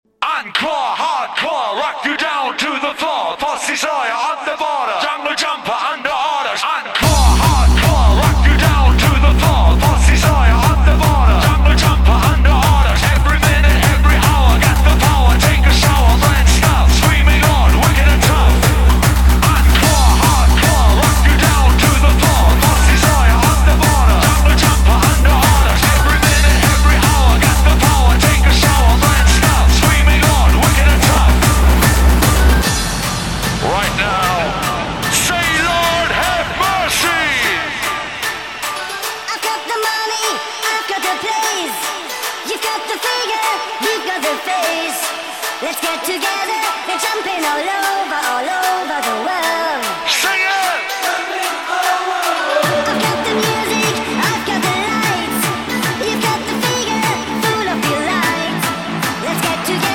Электронная
Новый альбом в стиле джамп